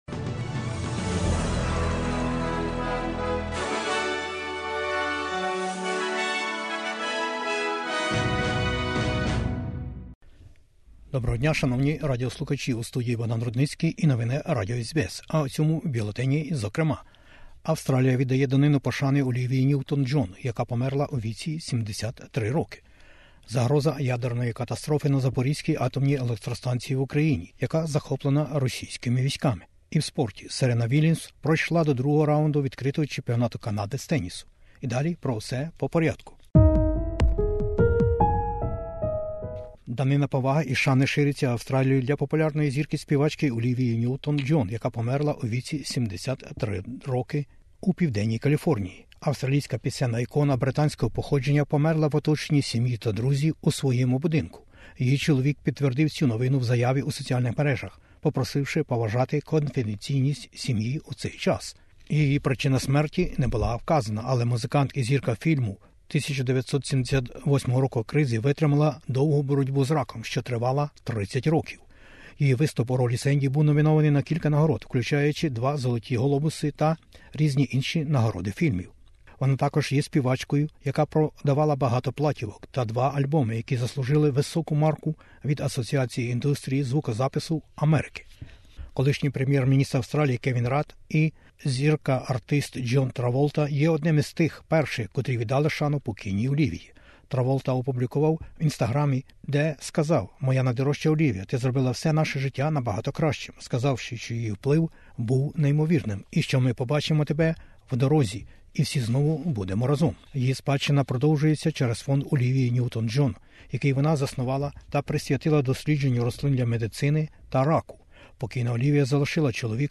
SBS News in Ukrainian - 9/08/2022